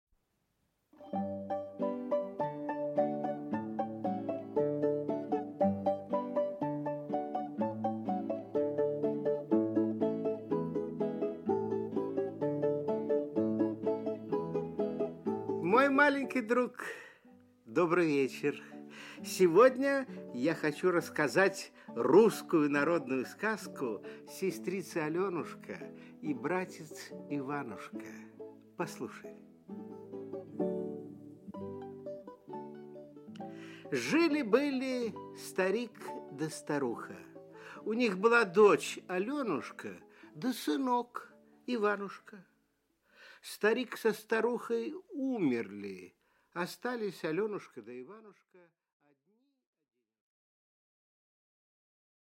Не послушал Иванушка свою сестру Алёнушку, напился воды из следа от козьего копытца да и превратился в козленочка… Читает Николай Литвинов